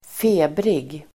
Ladda ner uttalet
Folkets service: febrig febrig adjektiv, feverish Uttal: [²f'e:brig] Böjningar: febrigt, febriga Synonymer: het Definition: som har feber, het feverish , Feberig , febrig , febervarm fevered , febrig